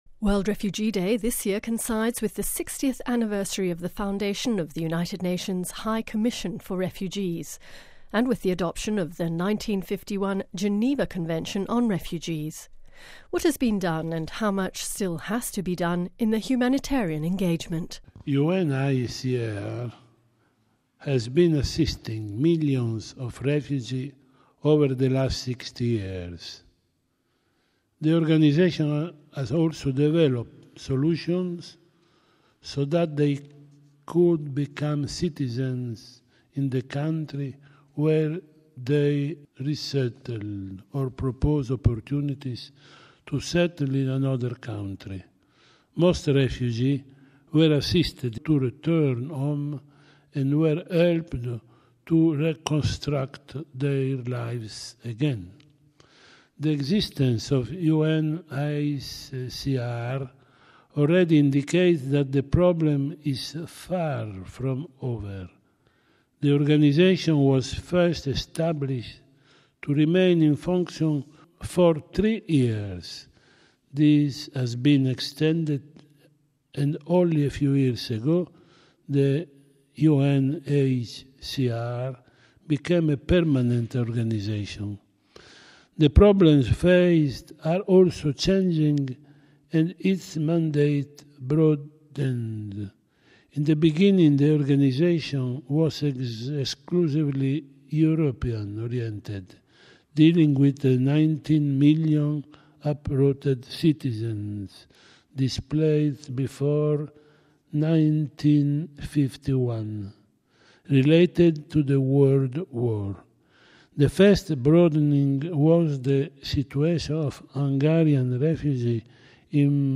On this important occasion Vatican Radio interviewed His Grace Antonio Maria Vegliò, President of the Pontifical Council for the Pastoral Care of Migrants and Itinerant People Q: World Refugee Day this year coincides with the 60 th anniversary of the foundation of the United Nations High Commission for Refugees and the adoption of the 1951 Geneva Convention on Refugees.